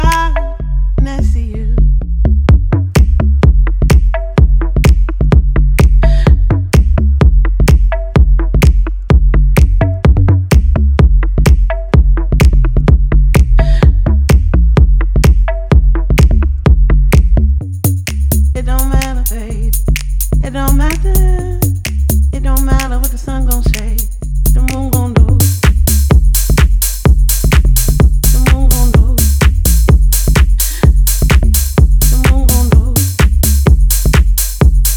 Жанр: Танцевальная музыка